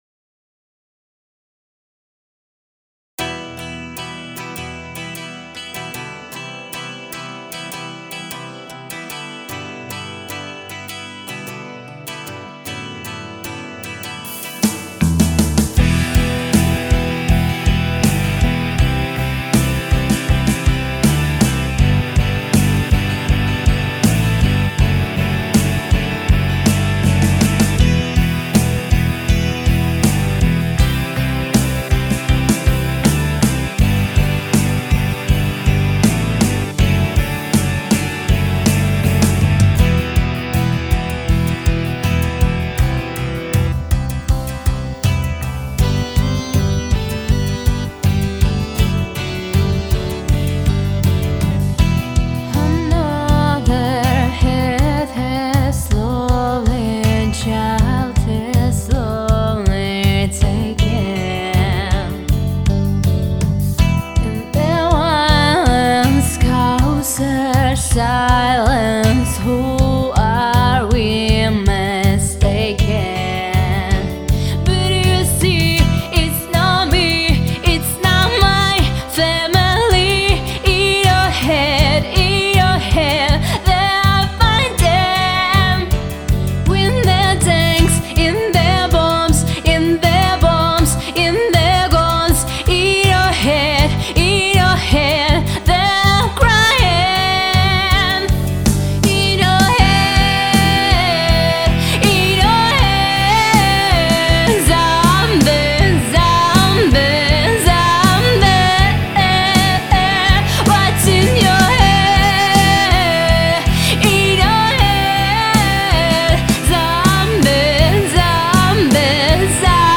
Ты пыталась как-то пройодлить, но хде же твой вкусный рэттл,